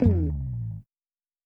Bass Slide.wav